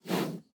Minecraft Version Minecraft Version snapshot Latest Release | Latest Snapshot snapshot / assets / minecraft / sounds / mob / panda / nosebreath1.ogg Compare With Compare With Latest Release | Latest Snapshot
nosebreath1.ogg